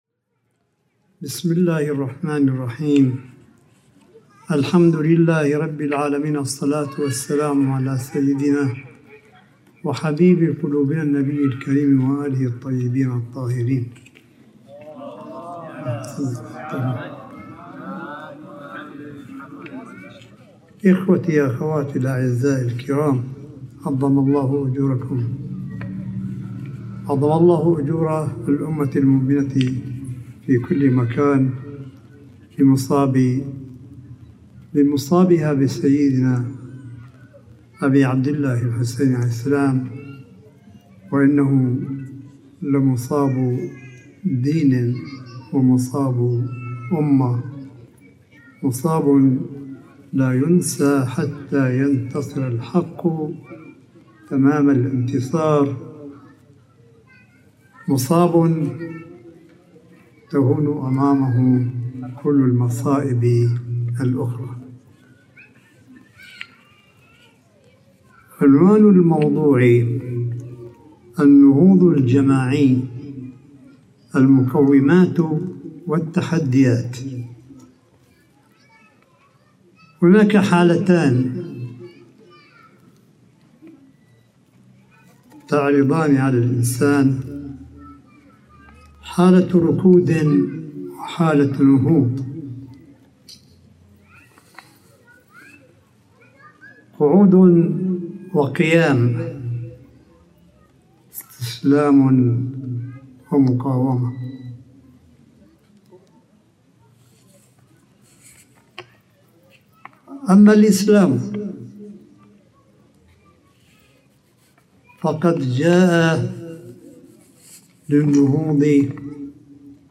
ايران_الكوثر: كلمة اية الله الشيخ عيسى قاسم في الليلة الرابعة من ليالي محرم للعام 1443 هجري في مدينة قم المقدسة